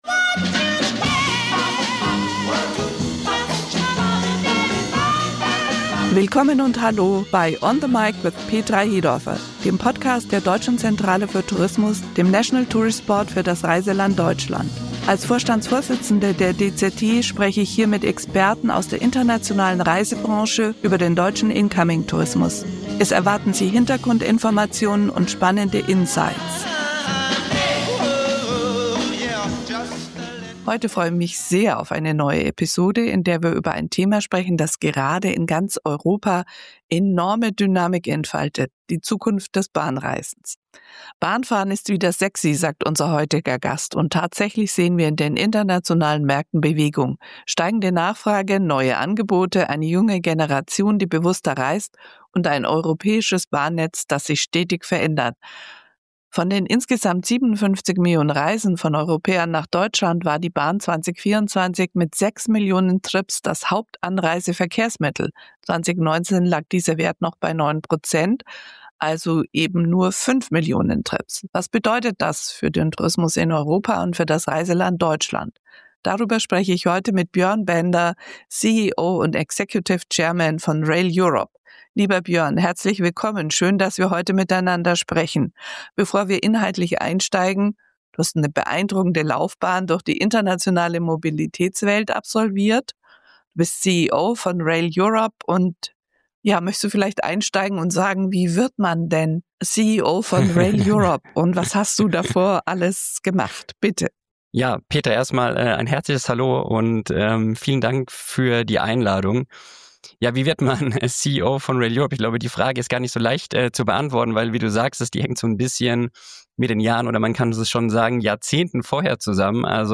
Und wie wird Bahnfahren zu einem komfortablen, digitalen und nachhaltigen Reiseerlebnis? Ein Gespräch über Chancen, Herausforderungen und die neue Bedeutung des Bahnfahrens in Europa.